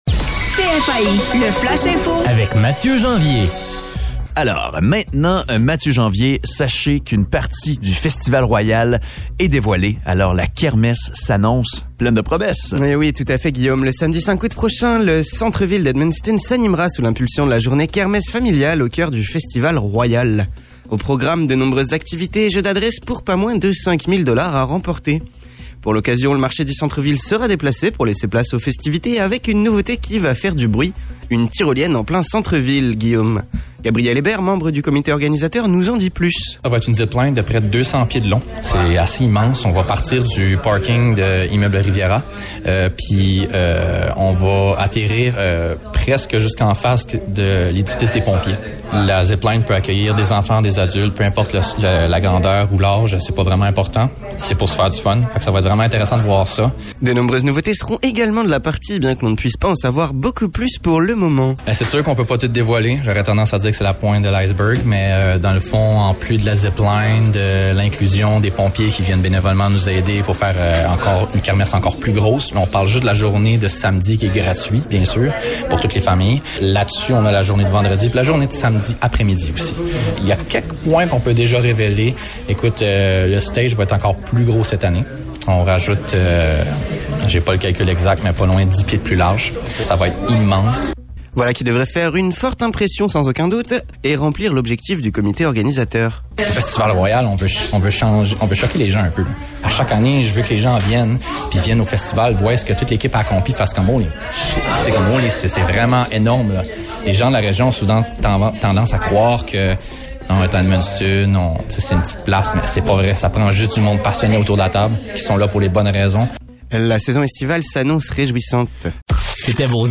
Le bulletin